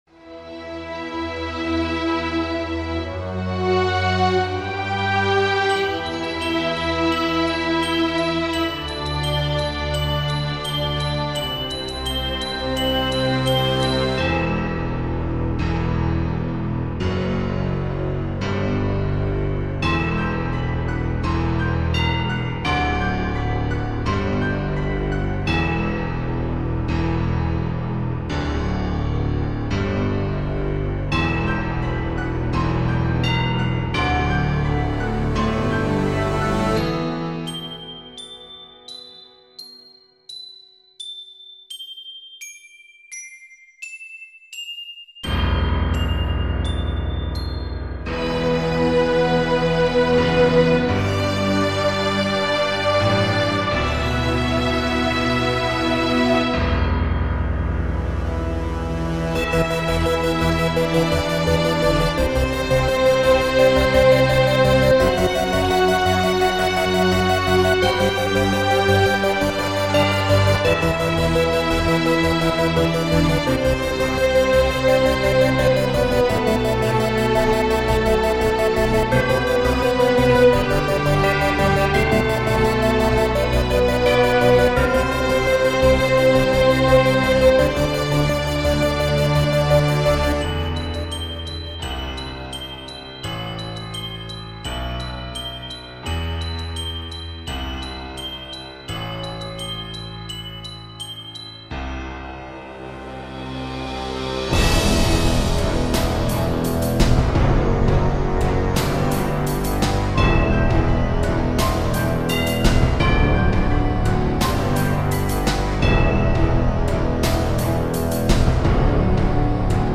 orchestral symphony techno hybrid